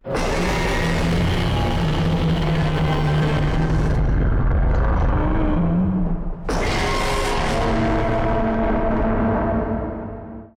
File:Clover (TCP) Roar.ogg
Clover_(TCP)_Roar.ogg